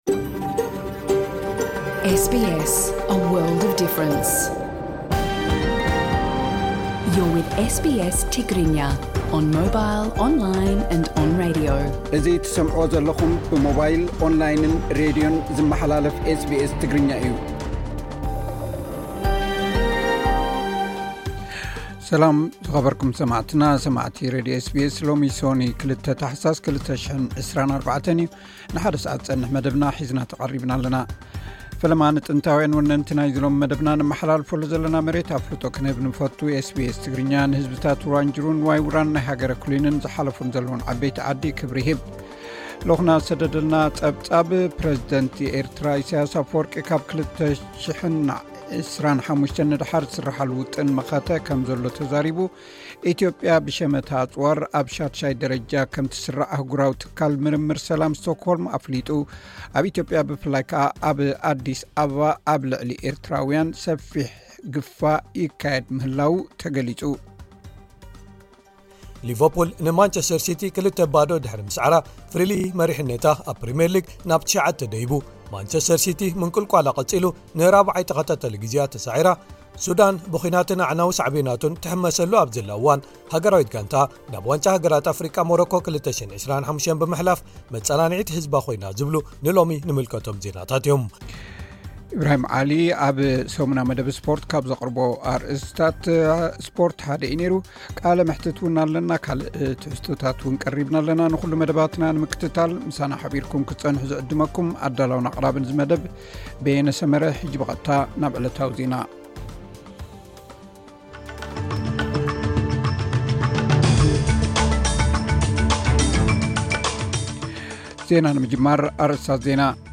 ቃለ መሕትት’ውን ኣለና።